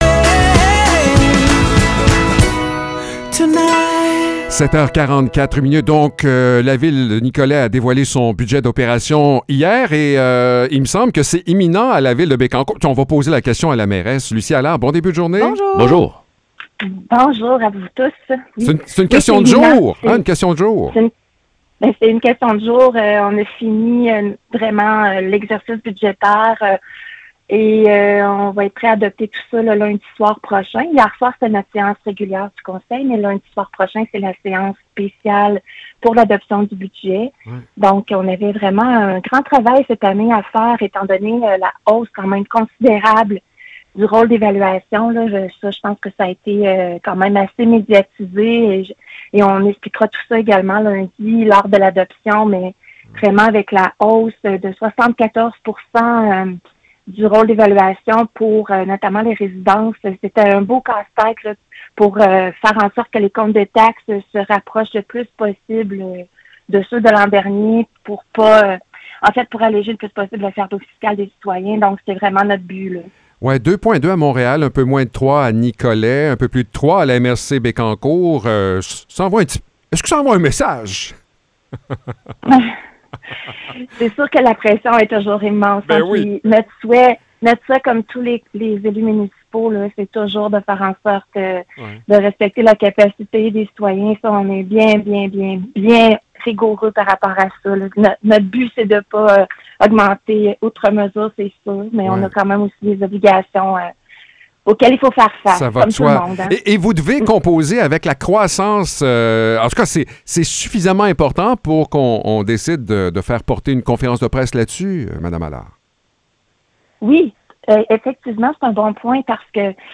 Échange avec la mairesse de Bécancour